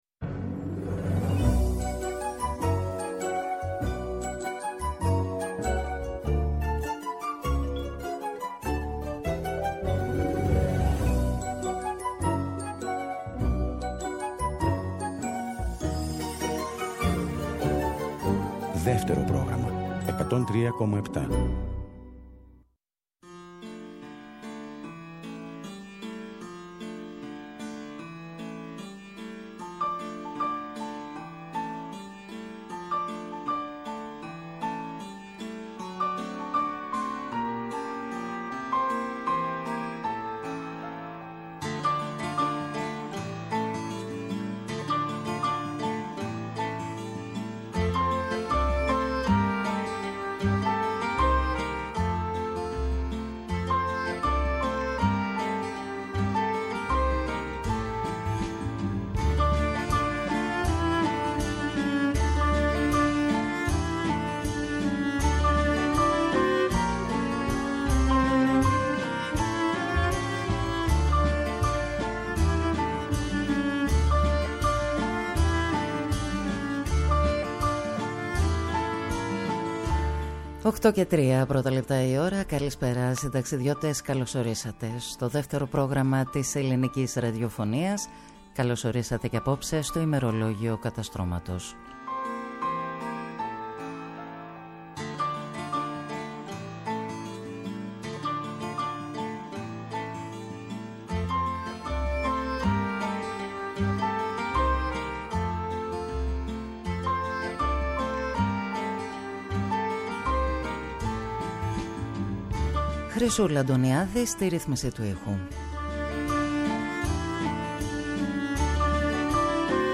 στο στούντιο του Δεύτερου Προγράμματος
Συνεντεύξεις